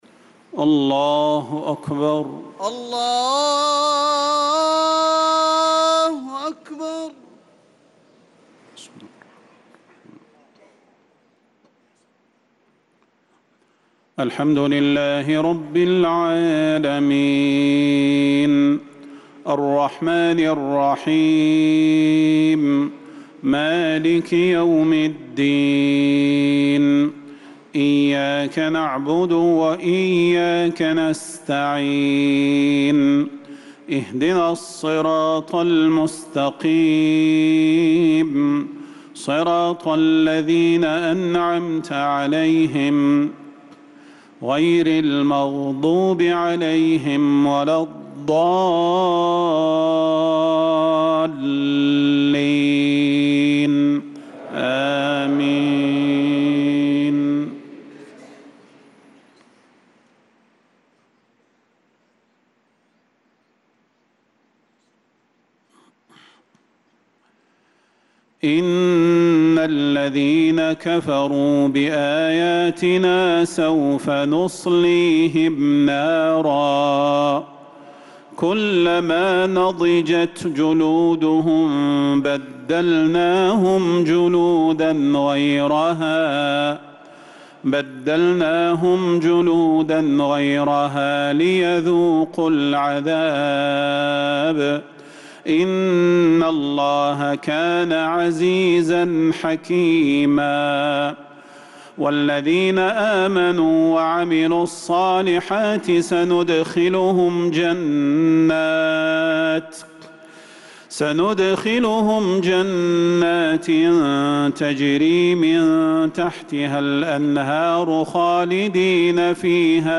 صلاة المغرب للقارئ صلاح البدير 7 ربيع الأول 1446 هـ